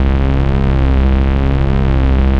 ballturret_loop.wav